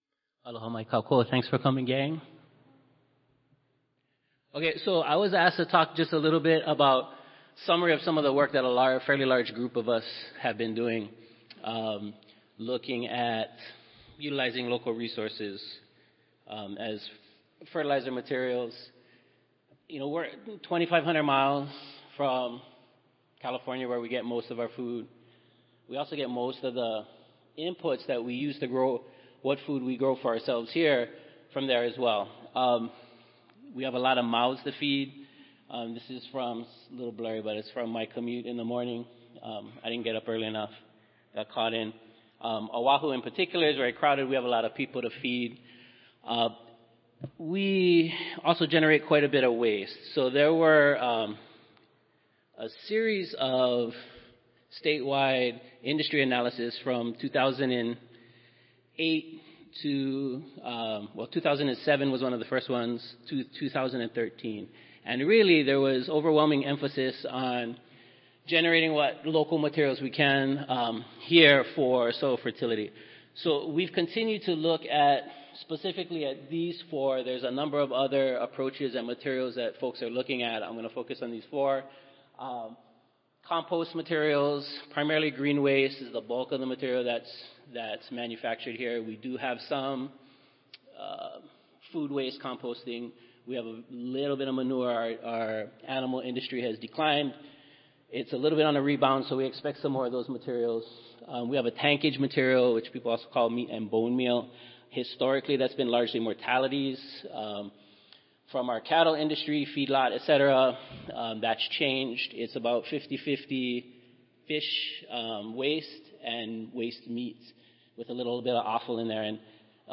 University of Hawaii at Manoa Audio File Recorded Presentation